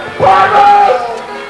May 23, 1996 - Dunwoody High School's "Battle of the Bands"
Go to the docks!!" as well as "Freebird!!" echoed throughout the night as power chords ripped through cover songs, and band after band took the stage.